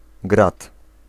Ääntäminen
Synonyymit maigret Ääntäminen France (Paris): IPA: [la ɡʁɛl] Tuntematon aksentti: IPA: /ɡʁɛl/ Haettu sana löytyi näillä lähdekielillä: ranska Käännös Ääninäyte Substantiivit 1. grad {m} Suku: f .